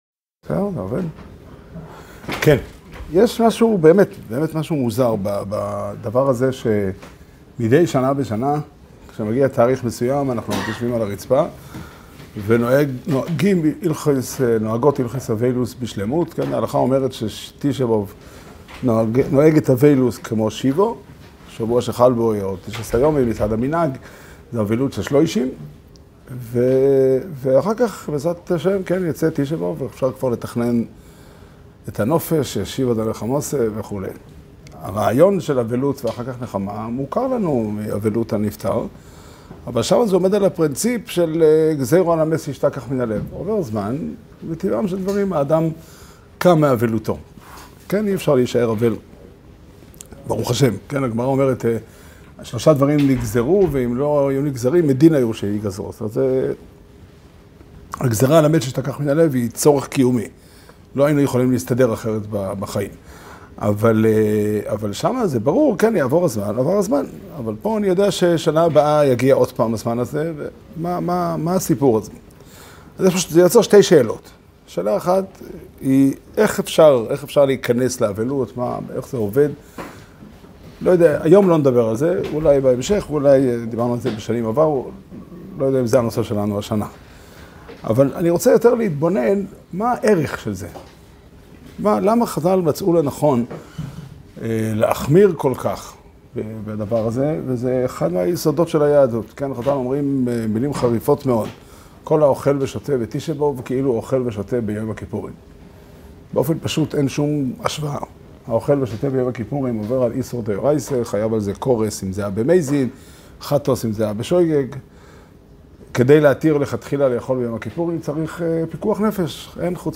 שיעור שנמסר בבית המדרש פתחי עולם בתאריך י"ט תמוז תשפ"ד